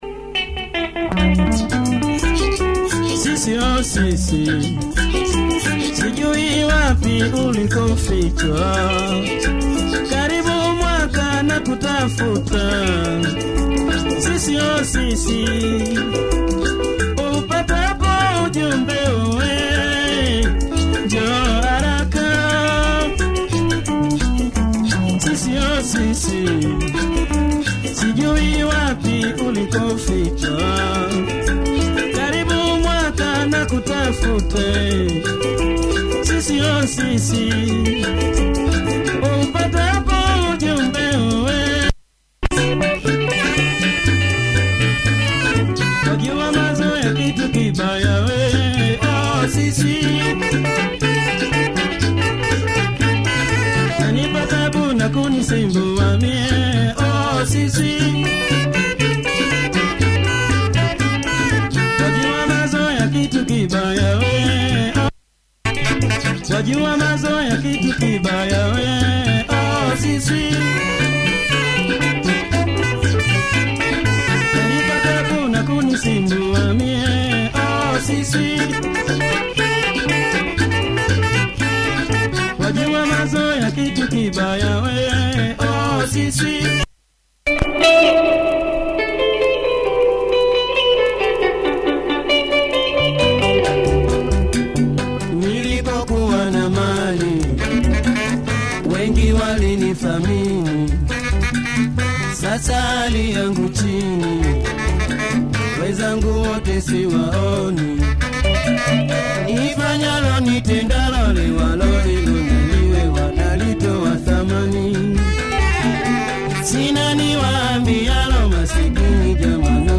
Nice early seventies double sider
popular Tanzanian band